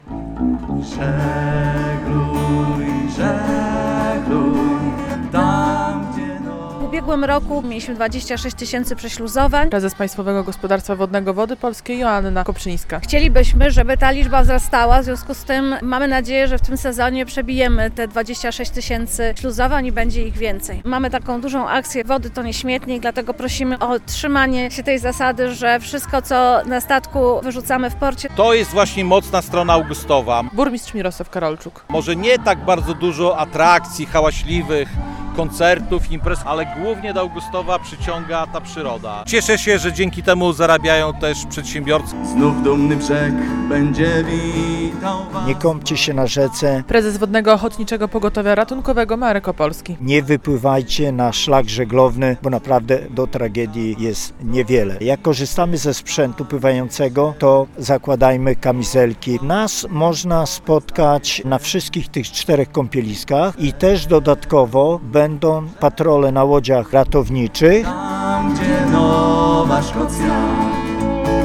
Podczas pikniku zorganizowanego przez Państwowe Gospodarstwo Wodne Wody Polskie mieszkańcy oraz turyści mogli między innymi obejrzeć najnowszy sprzęt wojskowy, ćwiczyć udzielanie pierwszej pomocy i dowiedzieć się wielu ciekawostek przyrodniczych.
Prezes Państwowego Gospodarstwa Wodnego Wody Polskie Joanna Kopczyńska liczy na dobry sezon.